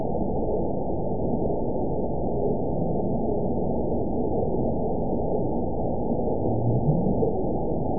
event 920524 date 03/28/24 time 21:53:51 GMT (1 year, 1 month ago) score 9.33 location TSS-AB02 detected by nrw target species NRW annotations +NRW Spectrogram: Frequency (kHz) vs. Time (s) audio not available .wav